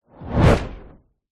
На этой странице собраны звуки ударной волны после взрыва — от глухих ударов до резких перепадов давления.
Звук мощного удара воздуха и металла, шум сопротивления, резкий порыв ветра